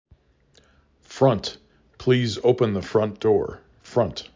Local Voices
Iowa